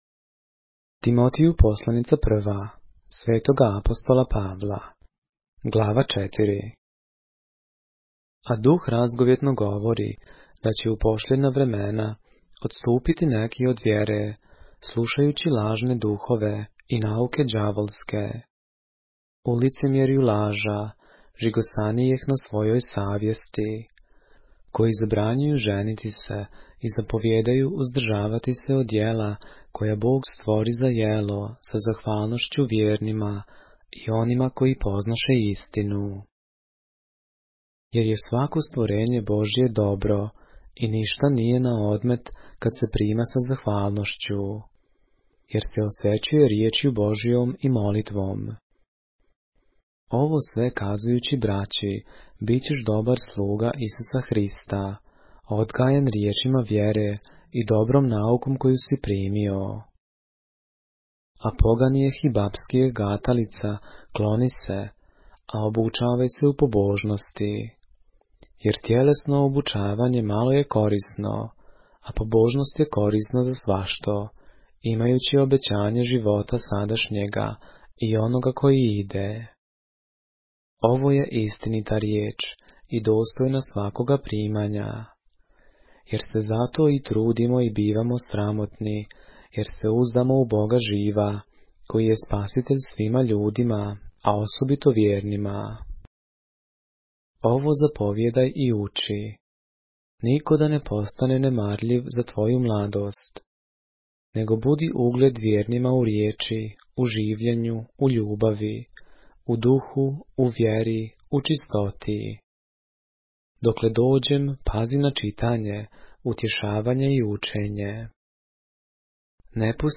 поглавље српске Библије - са аудио нарације - 1 Timothy, chapter 4 of the Holy Bible in the Serbian language